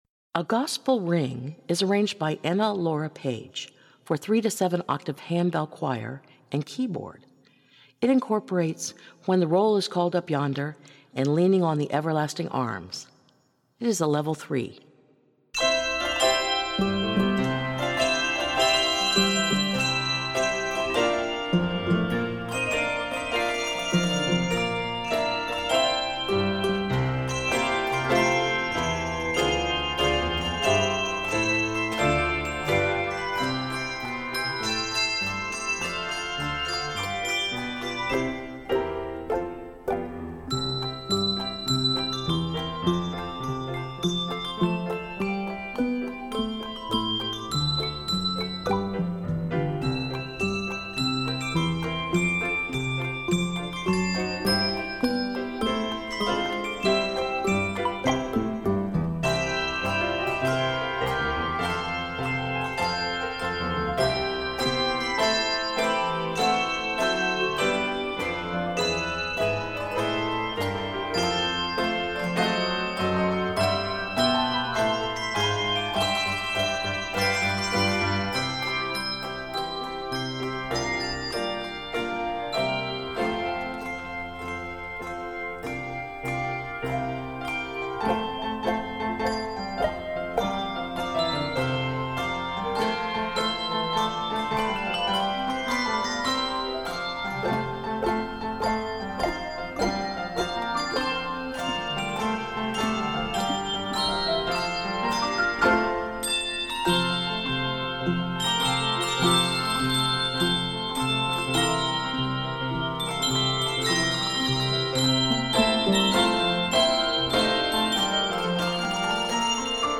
This energetic selection combines two upbeat gospel hymns
is scored in C Major.
Octaves: 3-7